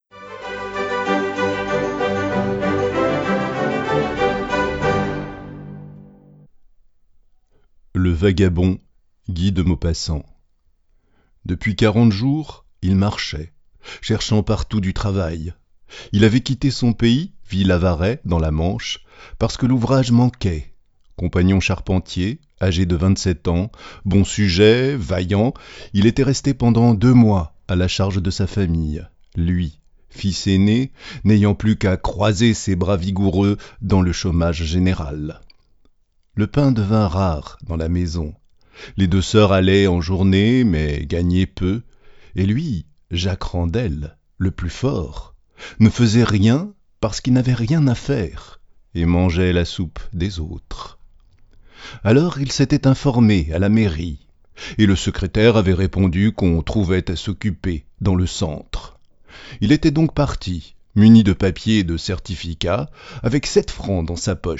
livre_audio_francais_1min.wav